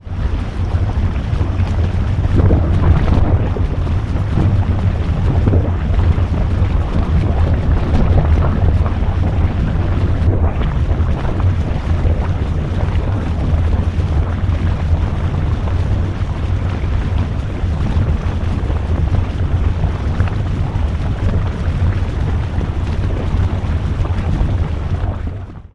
Звуки гейзеров
Шум водопада от гейзера